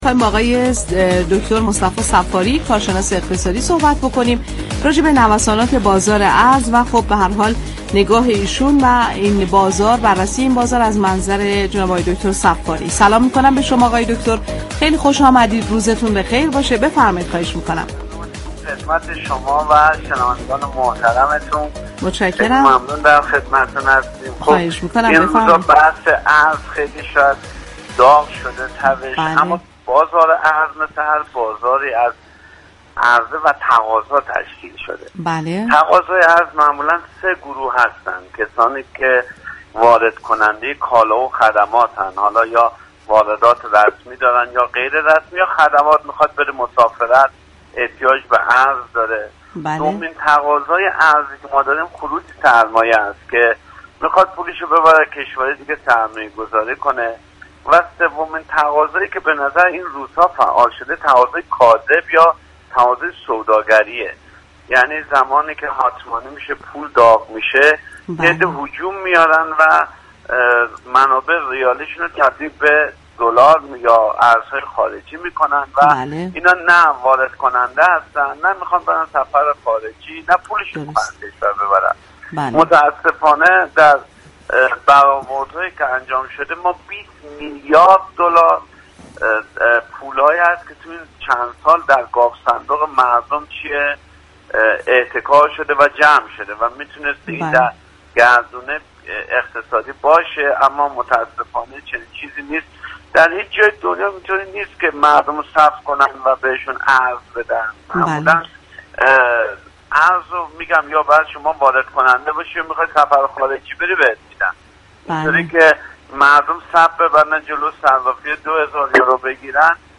در گفت و گو با "بازار تهران" رادیو تهران